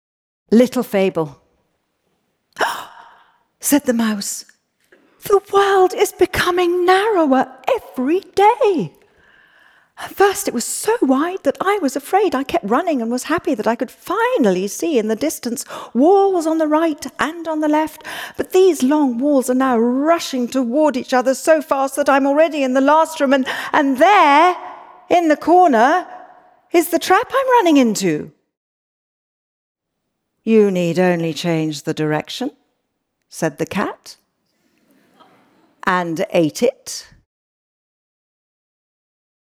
Kristin Scott Thomas Reads Kafka